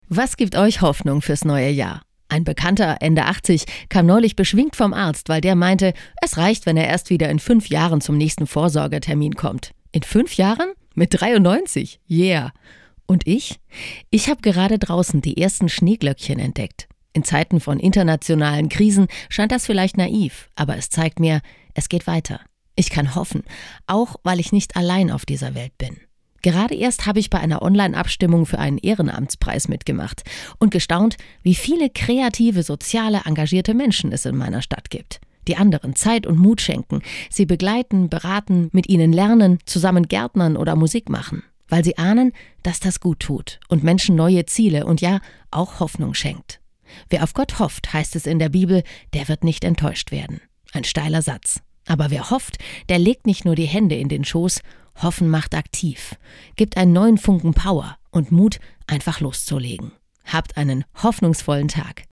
Startseite > andacht > Ich hoff doch